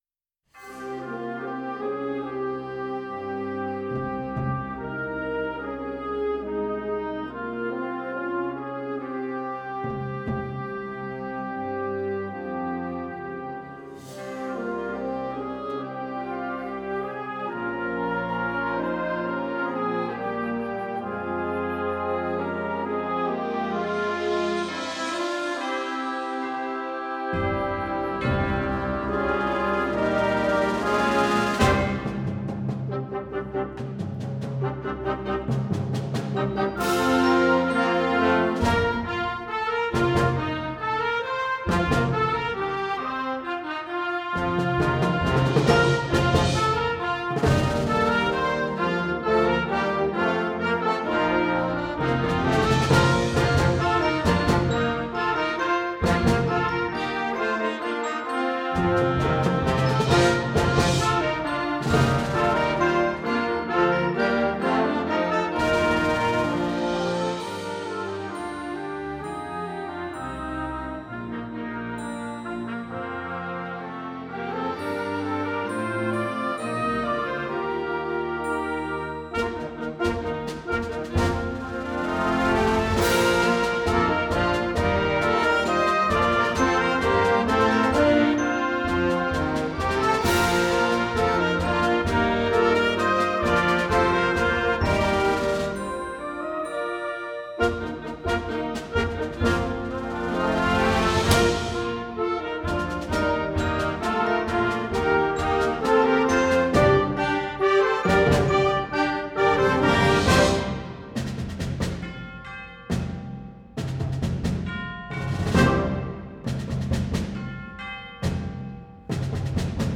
Voicing: Flex Band